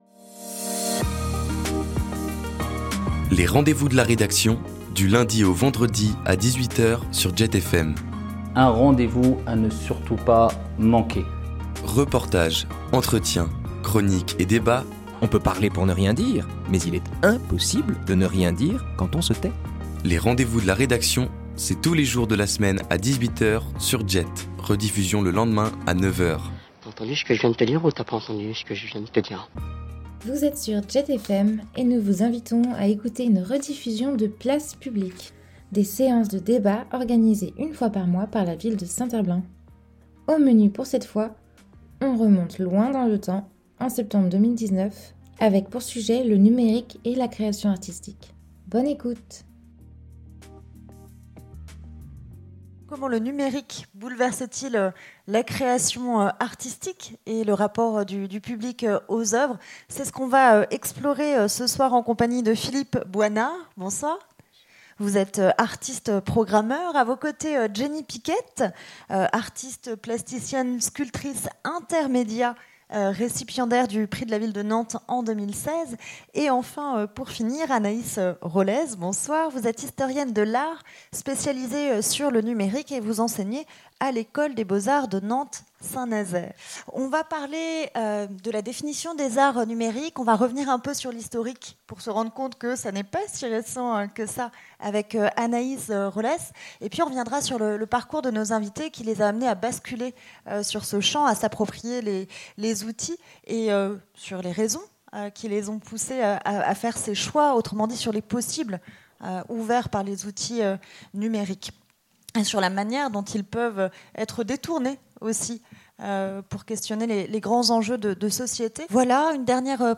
Dimanche 28 mars, à Nantes et dans toute la France, une journée de mobilisation était organisée pour réclamer une loi climat plus efficace et réellement sans filtre. Retour donc sur cette journée de mobilisation accompagnée par un soleil dont la chaleur nous a redonné le sourire mais nous rappelle par la même occasion l’urgence de la situation.